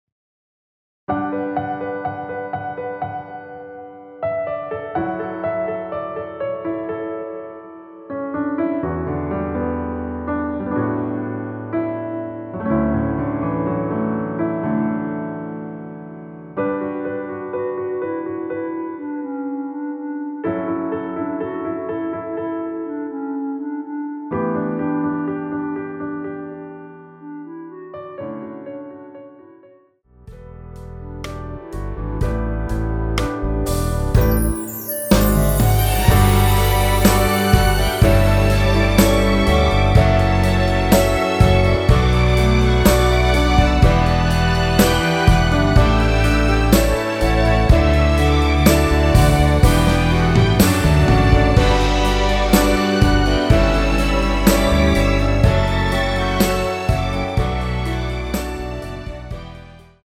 원키에서(-6)내린 멜로디 포함된 MR입니다.(미리듣기 확인)
앞부분30초, 뒷부분30초씩 편집해서 올려 드리고 있습니다.
중간에 음이 끈어지고 다시 나오는 이유는